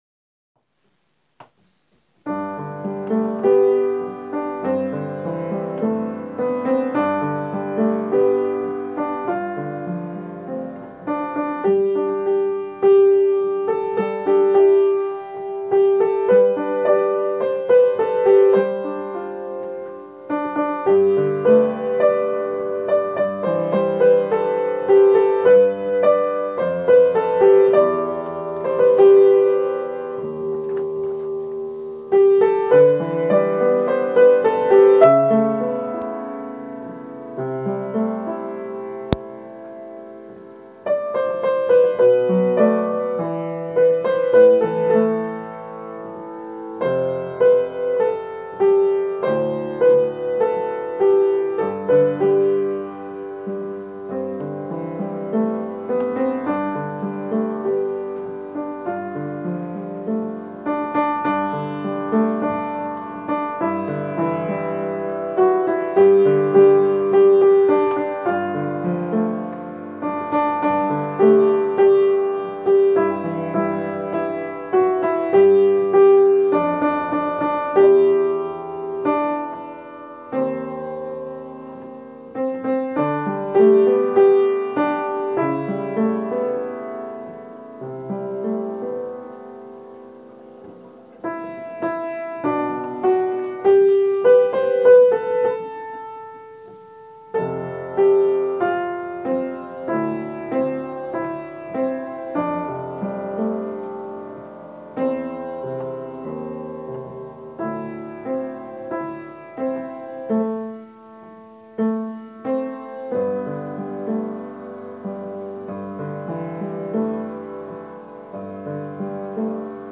Rehearsal Tracks - All - Mixed Voice Community Choir - Gabalfa, Cardiff
Rehearsal Tracks – All
Irish Blessing, Alto